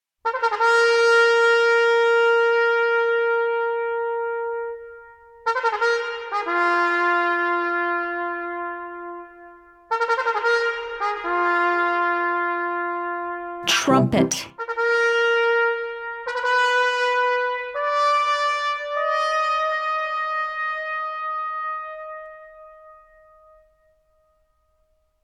TROMPETA
Por orde, a trompeta sería o membro da familia de elefantes coa trompa máis aguda.
trompeta.mp3